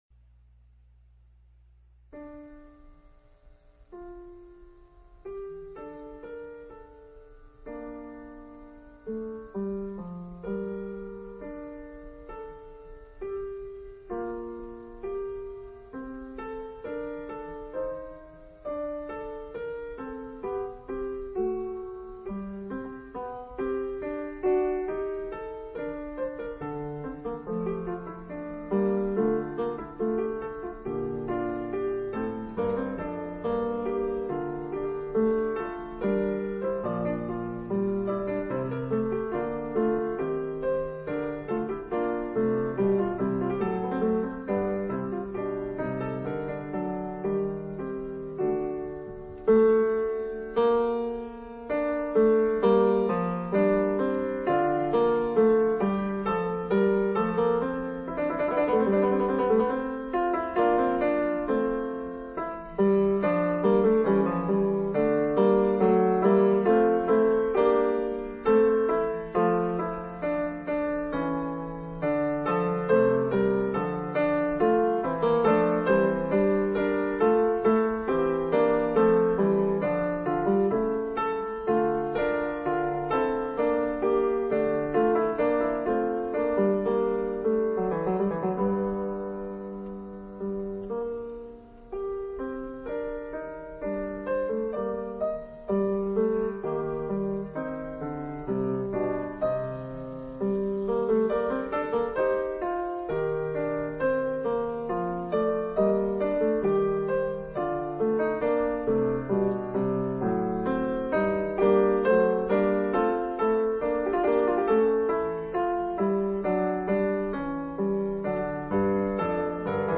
Glenn Gould – con leggero semi impercettibile canticchiare sporadico
tocco preciso e lucente – all’amato ⇨ Steinway CD318&sedia – 1959